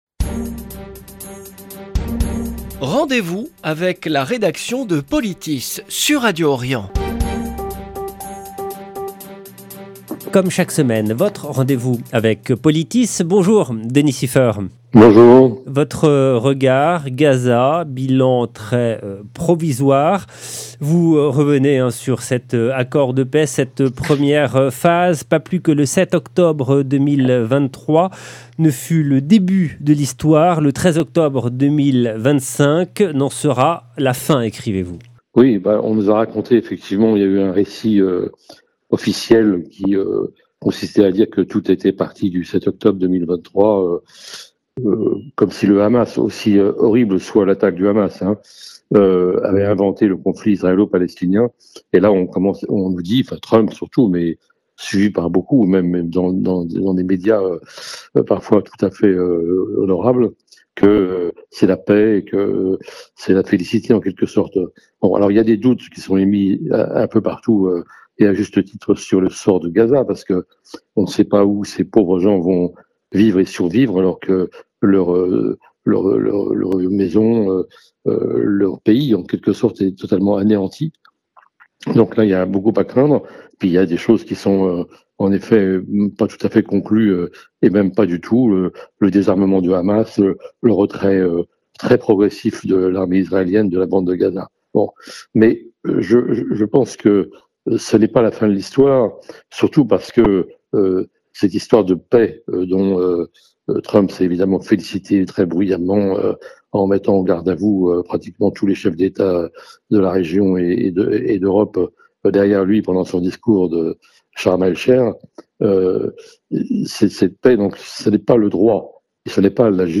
Chronique de Politis du 18/10/2025